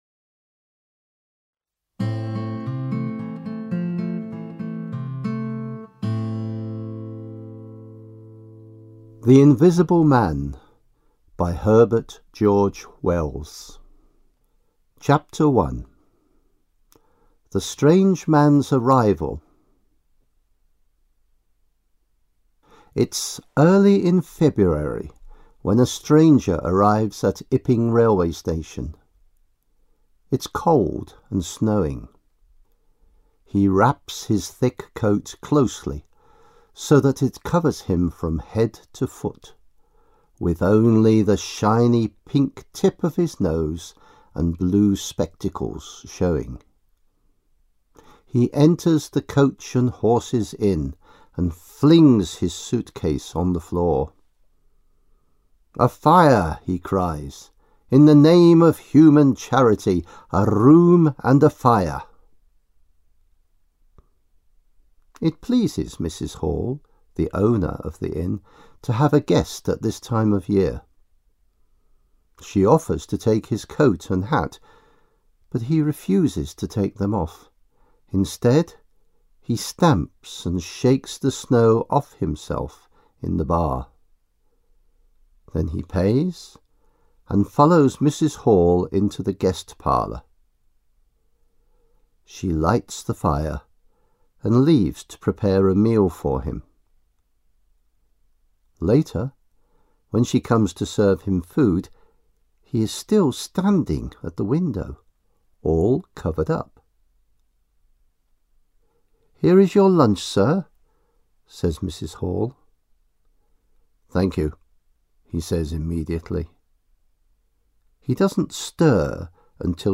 Audio kniha
Audioknihu namluvil rodilý mluvčí.